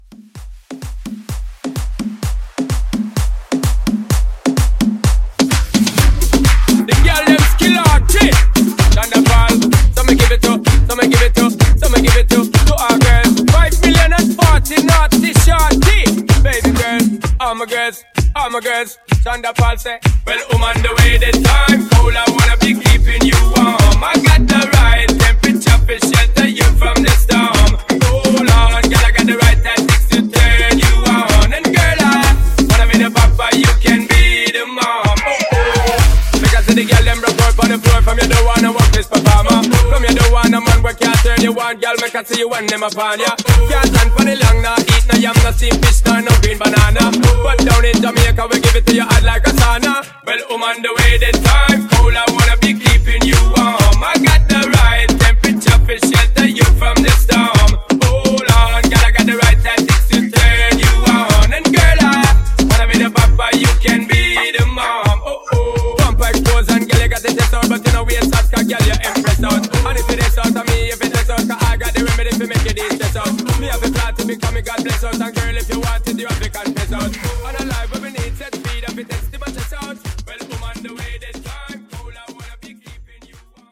Genre: 60's
Clean BPM: 126 Time